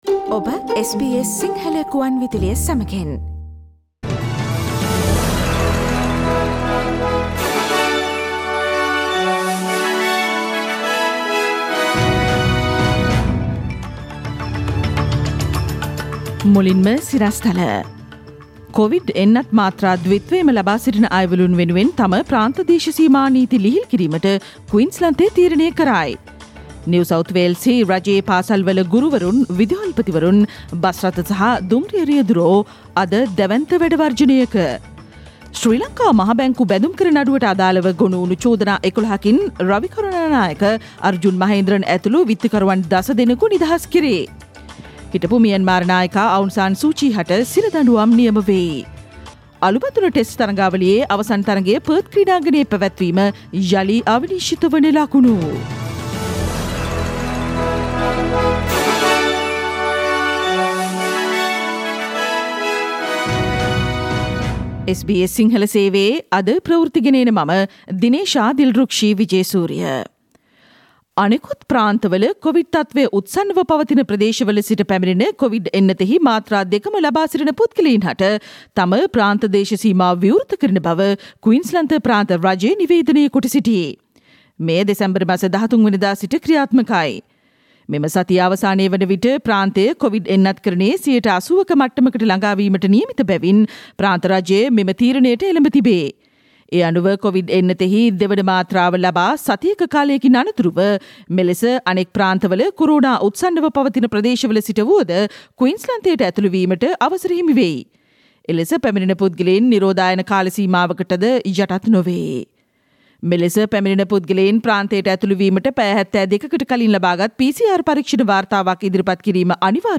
Click on the speaker icon on the photo above to listen to the SBS Sinhala Radio news bulletin on Tuesday 7th of December 2021